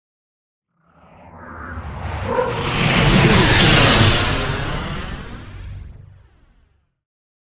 Whoosh large heavy
Sound Effects
dark
intense
whoosh